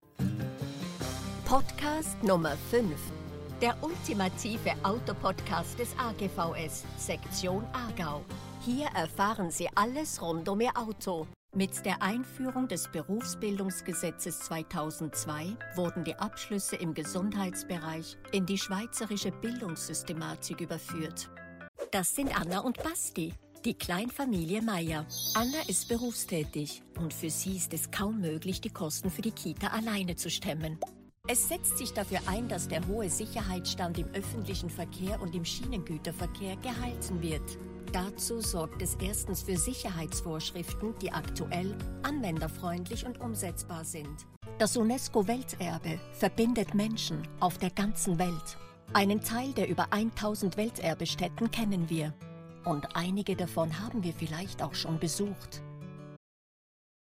Sprechprobe: Werbung (Muttersprache):